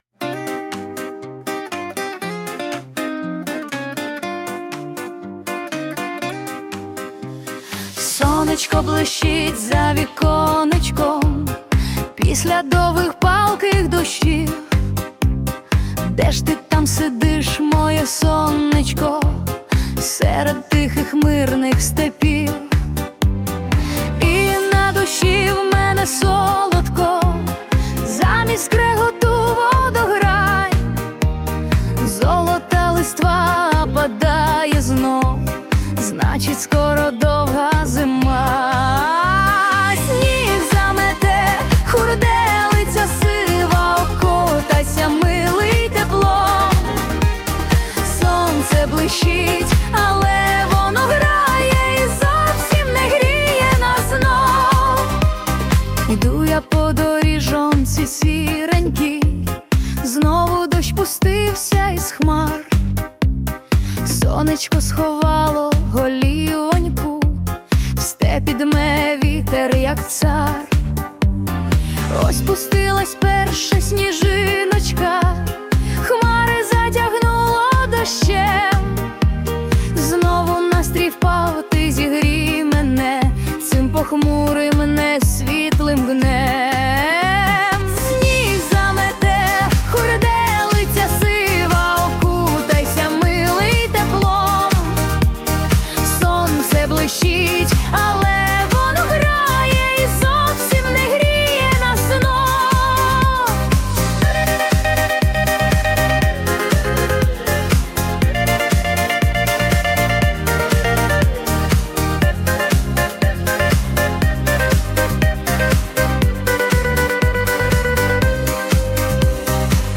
Стиль: Поп